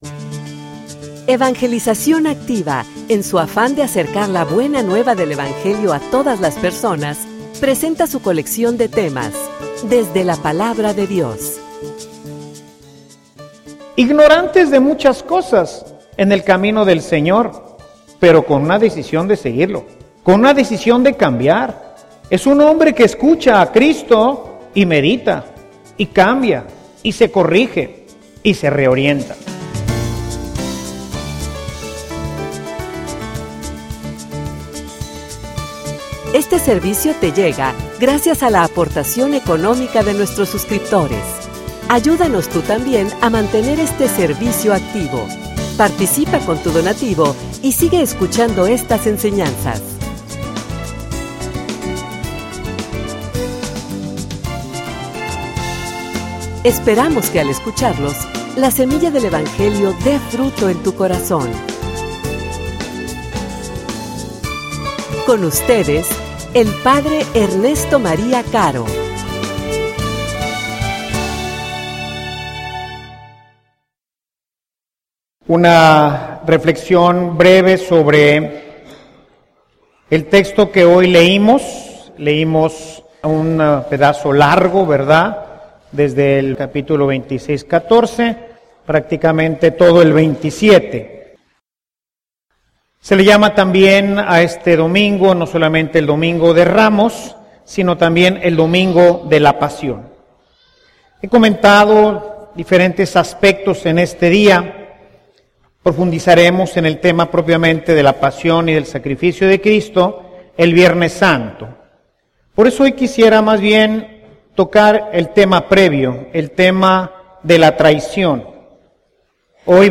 homilia_Cronica_de_una_traicion.mp3